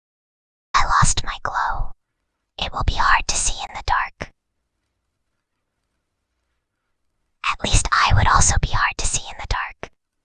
Whispering_Girl_16.mp3